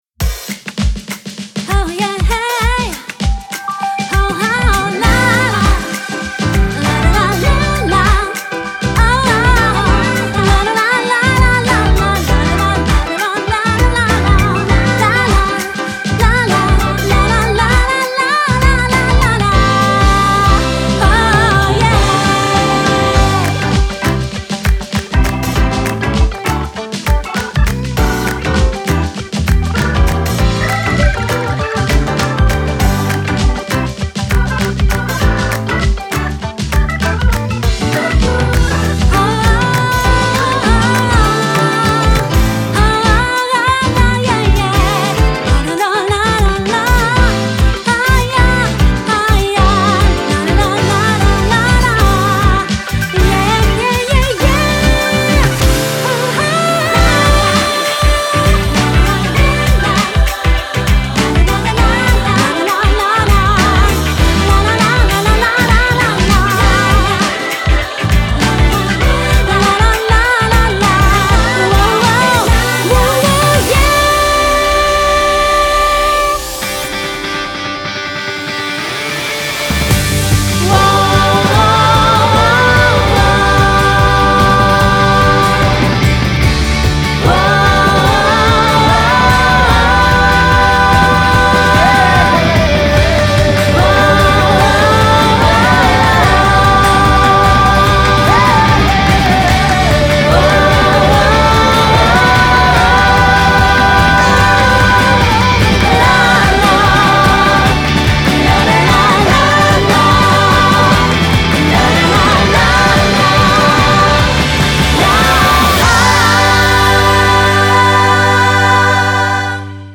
SONG
ROCK / POPS